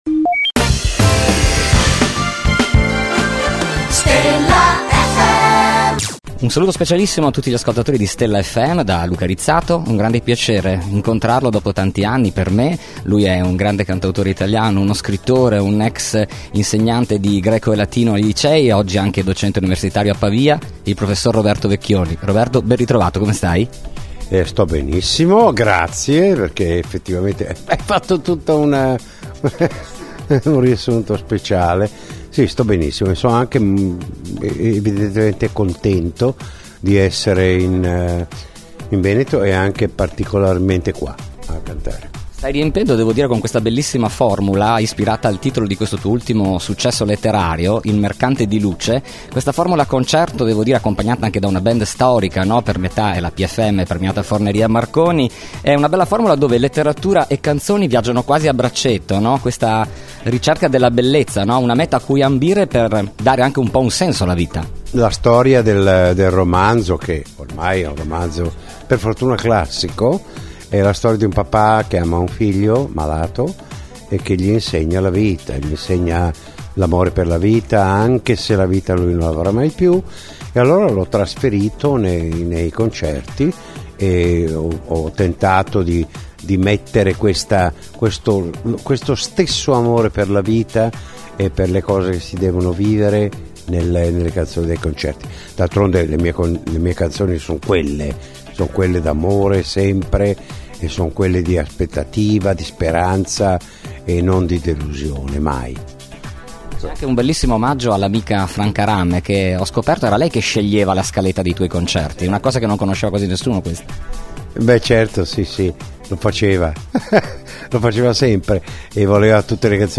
Intervista Roberto Vecchioni | Stella FM
Intervista-Roberto-Vecchioni.mp3